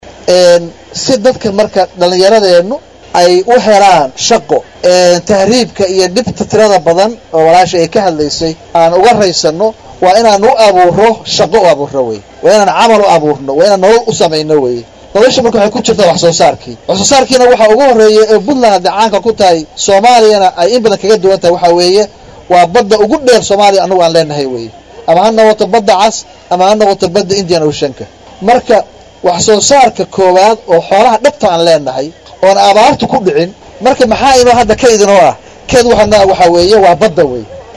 Madaxweyne Gaas oo ka hadlayay Xaflad diirada lagu saarayay sidii dhalinyarada safarka qatarta badan looga baajin lahaa ayaa sheegay in loo baahan yahay in Puntland go’aan cad ka gaarto dhalinyarada shaqo la’aanta ku qasbeysa inay dalkooda ka taagan,islamarkaana dhibaato kala kulmaan safaro kale oo dheer,isagoona carabka ku adkeeyay in dhalinyaradasi shaqo abuuris loo sameeyo.
Halkan Ka Dhageyso Codka Madaxweyne Dr. Gaas.